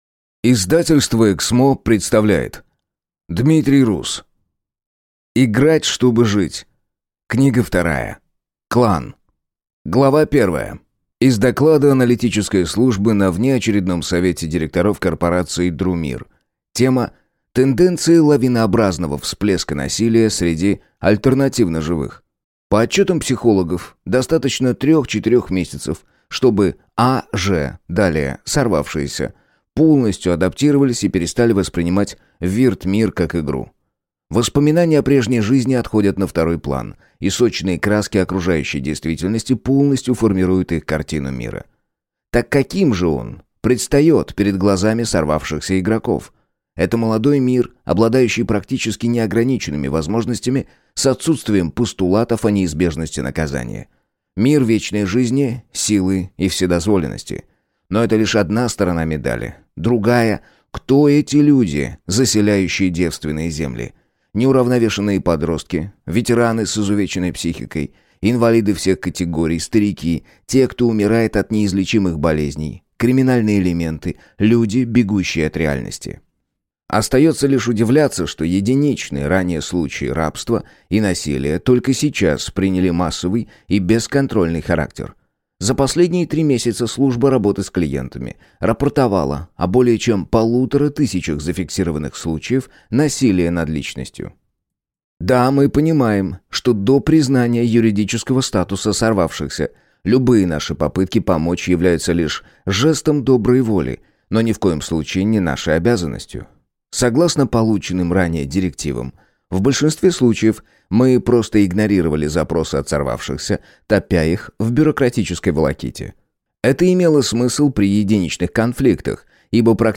Аудиокнига Играть, чтобы жить. Книга 2. Клан | Библиотека аудиокниг